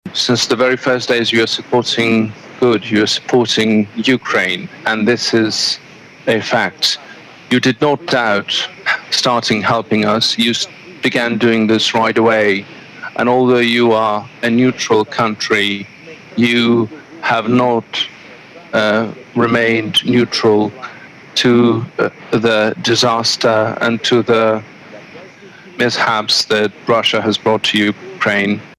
Addressing a joint sitting of the Oireachtas, Volodomyr Zelensky said Russia doesn’t deserve to be in the circle of civilised countries.
President Zelensky thanked Irish people for the support they have shown to the people of Ukraine: